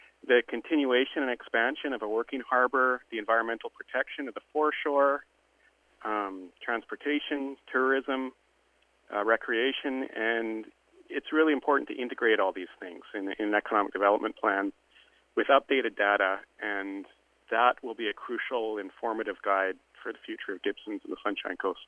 Council Silas White says having something like this will go a long way.